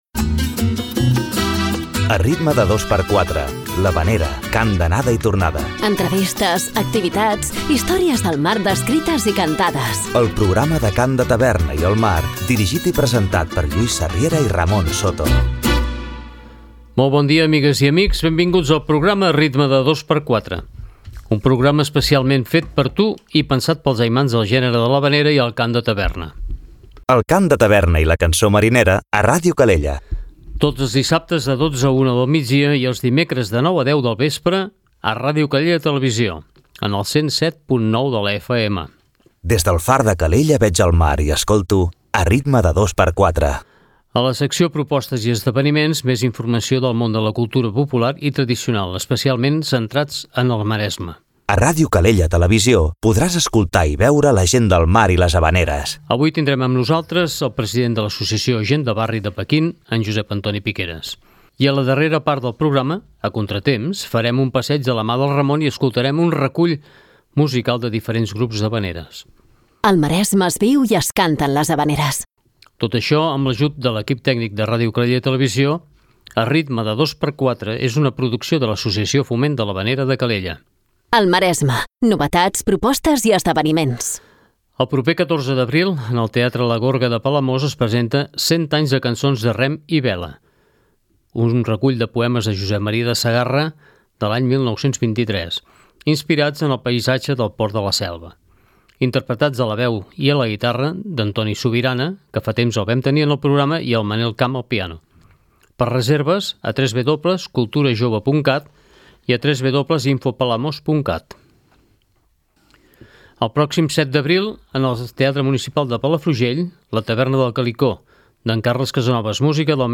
També com sempre a l'espai "A Contratemps" tindrem un recull variat de temes mariners.